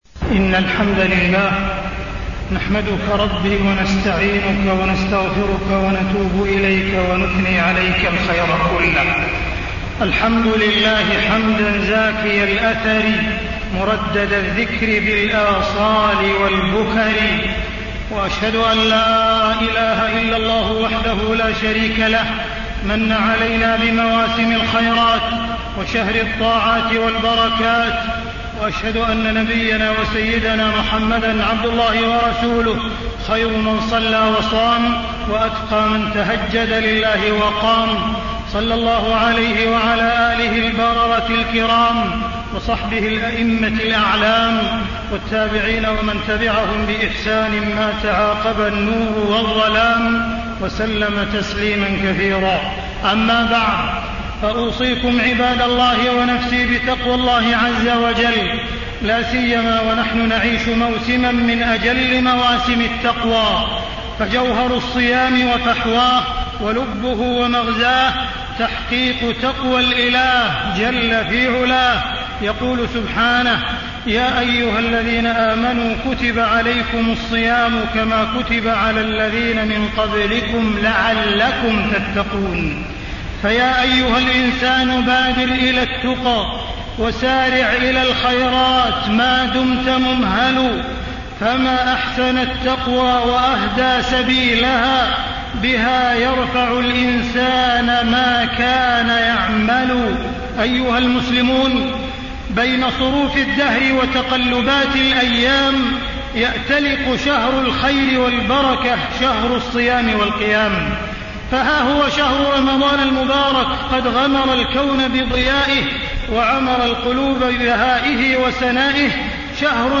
تاريخ النشر ٦ رمضان ١٤٣٥ هـ المكان: المسجد الحرام الشيخ: معالي الشيخ أ.د. عبدالرحمن بن عبدالعزيز السديس معالي الشيخ أ.د. عبدالرحمن بن عبدالعزيز السديس مقاصد الصيام وأسراره